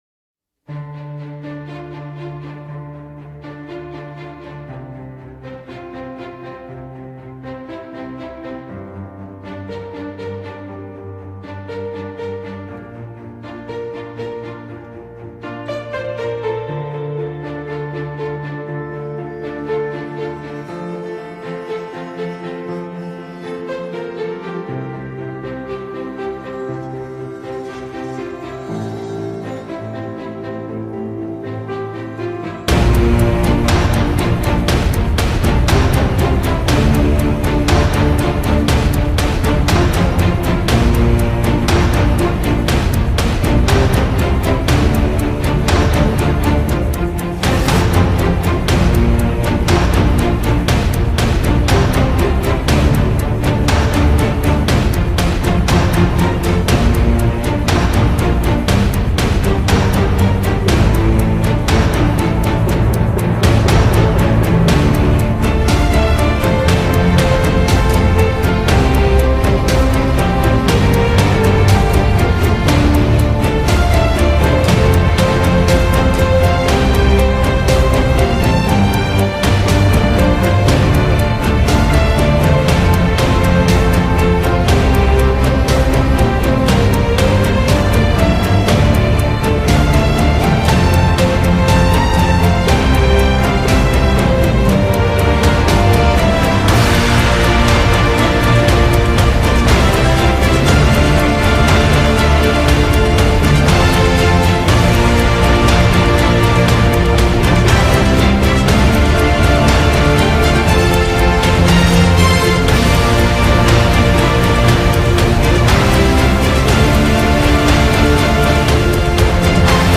KEfuoBO827j_Musica-Épica---Victory.mp3